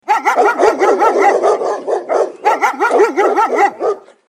Dogs Barking Sound Effect Download: Instant Soundboard Button
Dog Barking Sound2,672 views